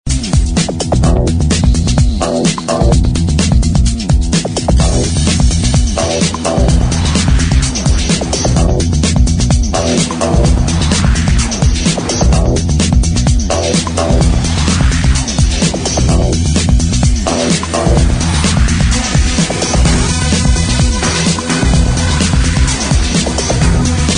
Bangning Minimal Track...